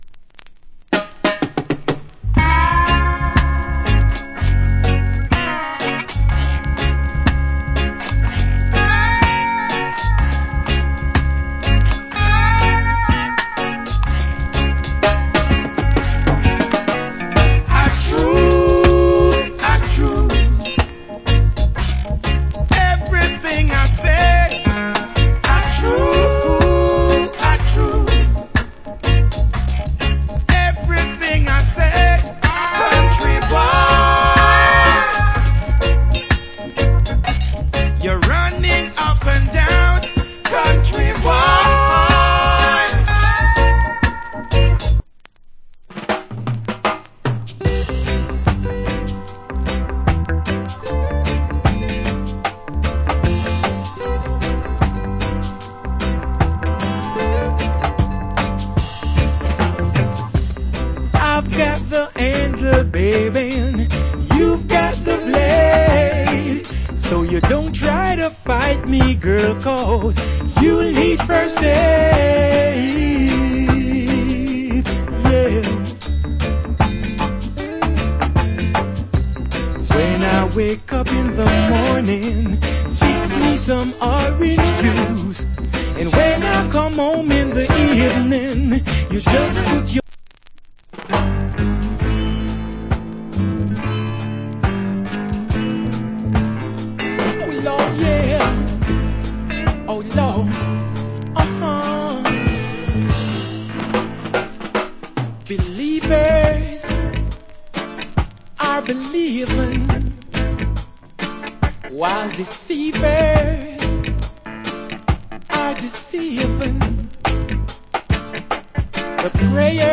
Great Roots Rock & Reggae.